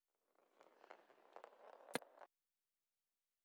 滚珠撞到鞋子.wav